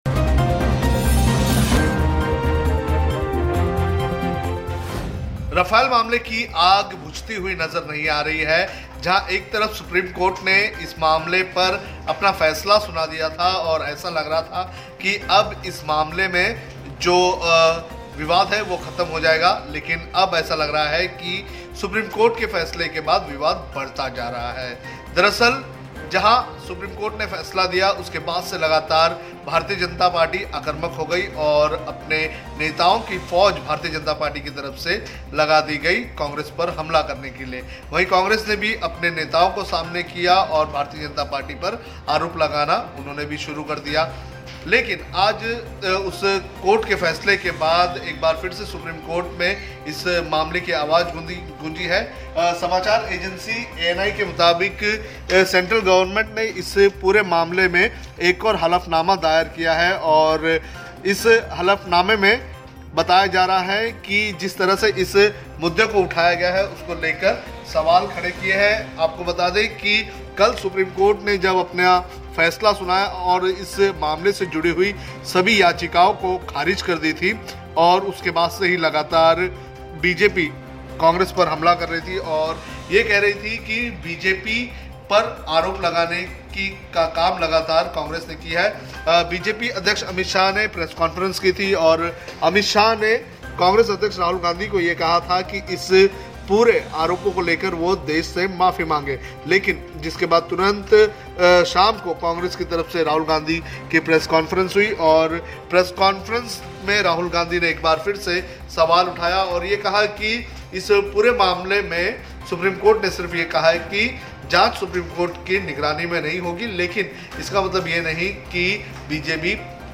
न्यूज़ रिपोर्ट - News Report Hindi / Rafale Deal : केंद्र सरकार ने सुप्रीम कोर्ट में दायर किया नया हलफनामा